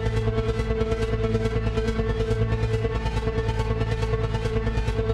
Index of /musicradar/dystopian-drone-samples/Tempo Loops/140bpm
DD_TempoDroneB_140-A.wav